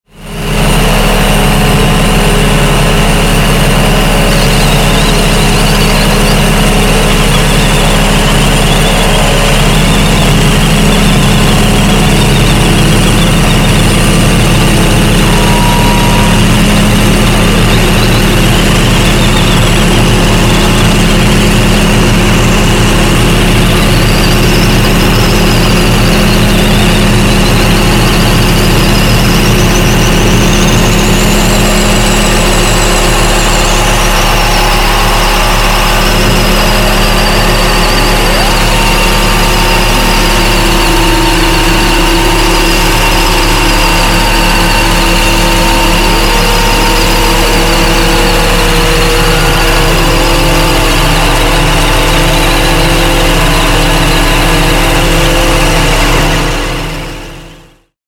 渦巻くPM2.5（微小粒子状物質）の如く広がるノイズと、
音奏者。
2006年から即興演奏を始める。
Genre:　Noise
Style:　Industrial, Junk, Electronics